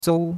zou1.mp3